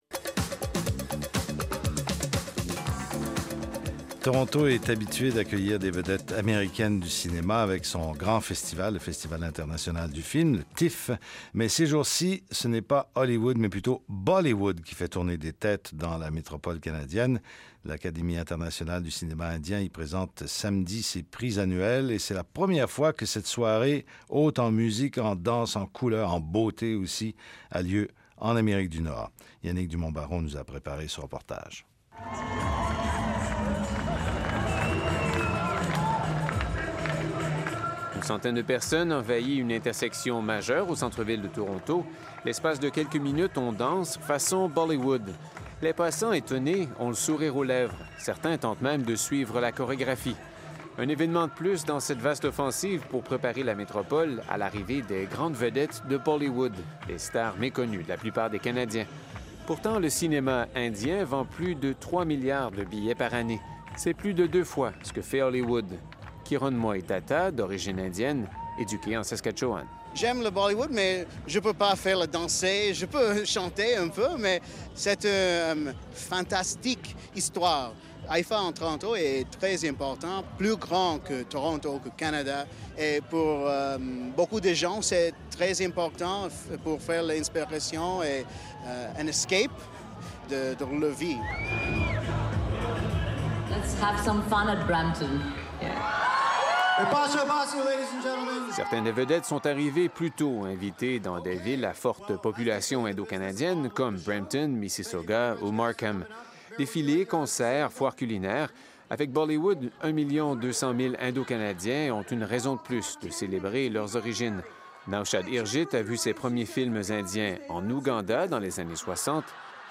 par | Classé dans : Archives, Communauté indienne, Reportages | 0